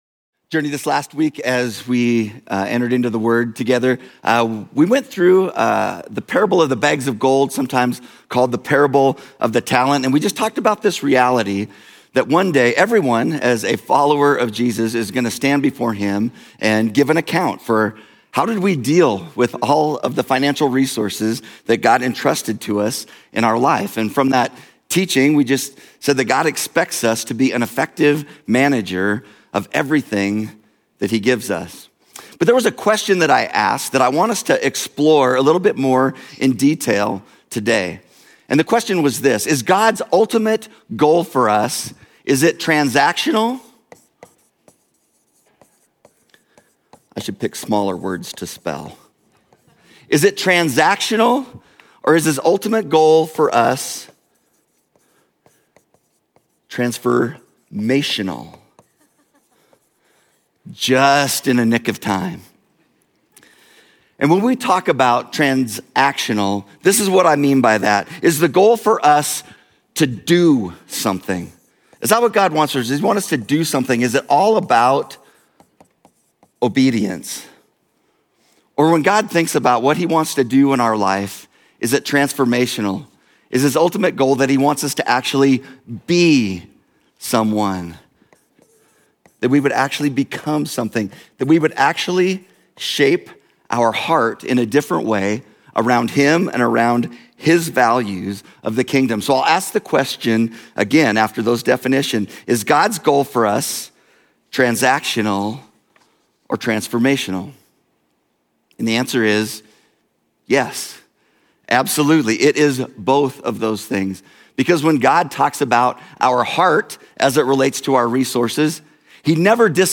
Our Generosity: Transactional Vs. Transformational Journey Church Bozeman Sermons podcast